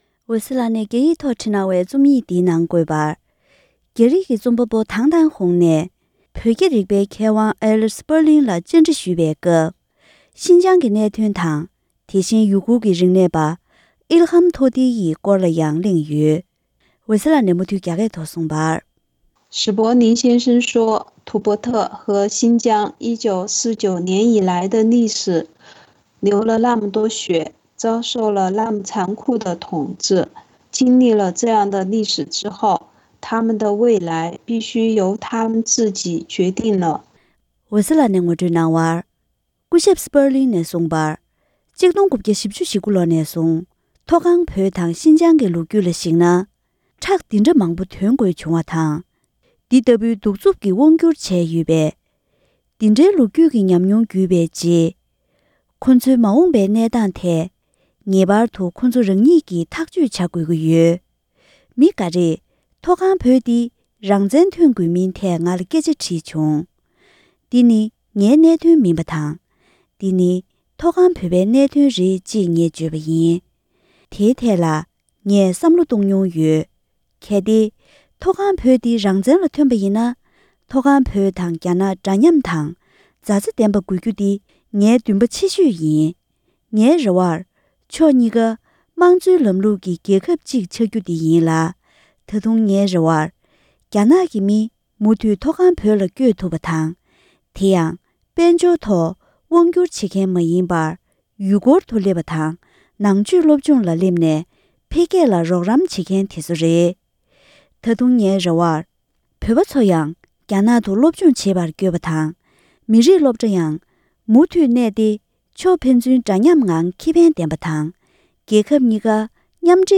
བོད་རྒྱའི་མཁས་པ་སྐུ་ཞབས་Elliot Sperlingལ་བཅའ་འདྲི་ཞུས་པ། ལེ་ཚན་དང་པོ།